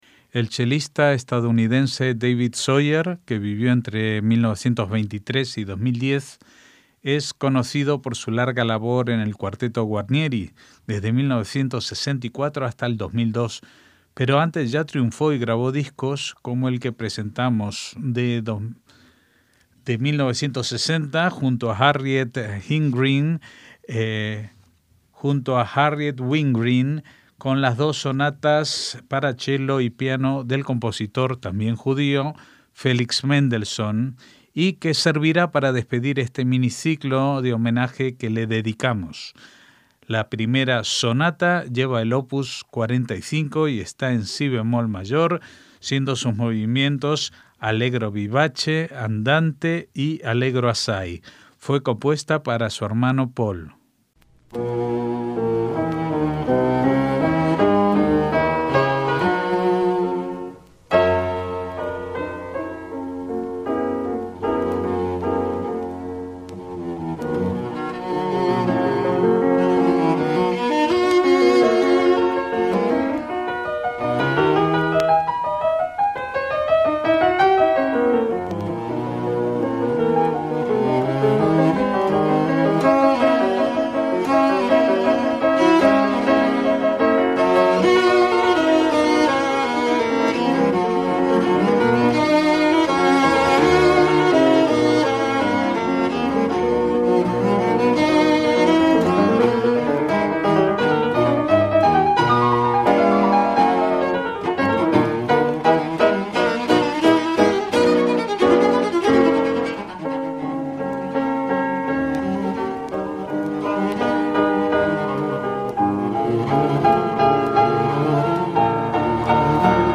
MÚSICA CLÁSICA
sonatas para chelo y piano
está en si bemol mayor